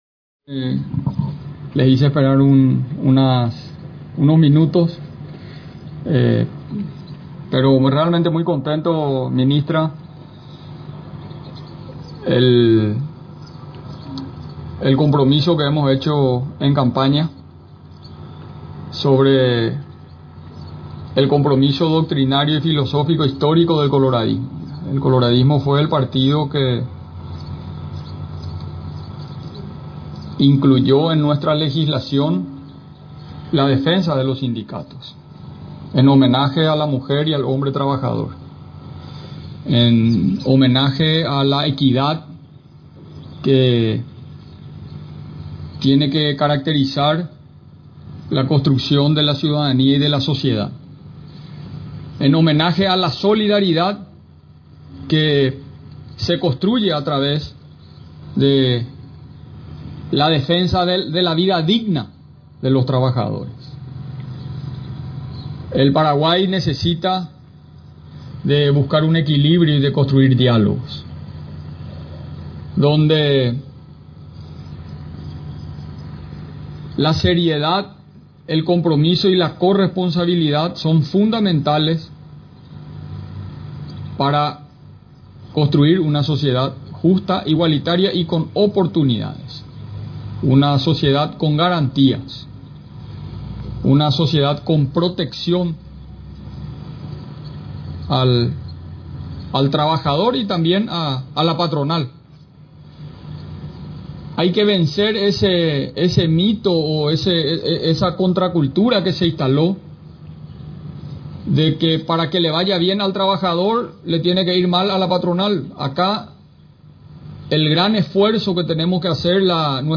Durante su discurso, el mandatario recordó uno de sus compromisos de campaña, que es la defensa de los trabajadores.